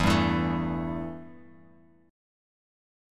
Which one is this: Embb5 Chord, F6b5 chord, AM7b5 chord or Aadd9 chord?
Embb5 Chord